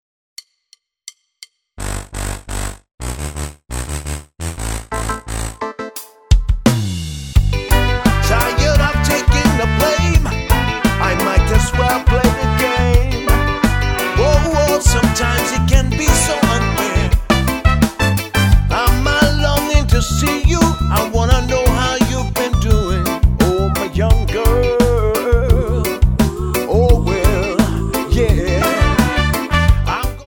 Tonart:G Multifile (kein Sofortdownload.
Die besten Playbacks Instrumentals und Karaoke Versionen .